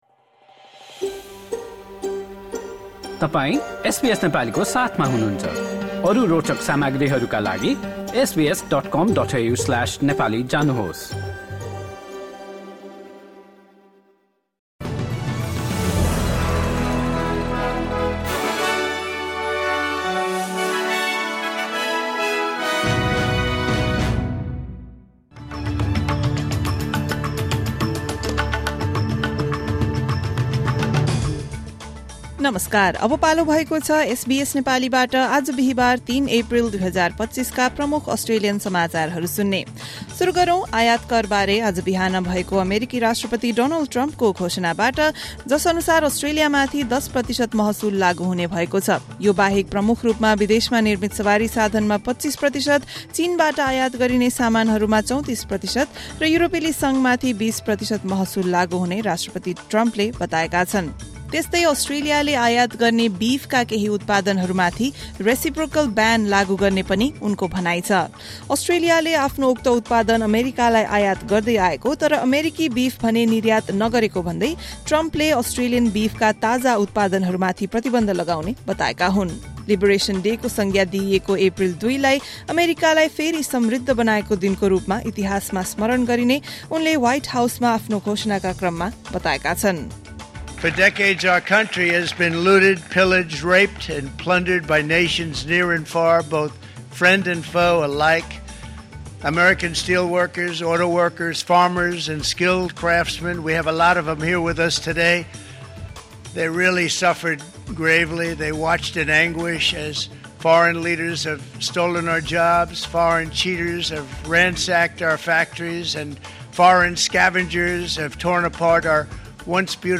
SBS Nepali Australian News Headlines: Thursday, 3 April 2025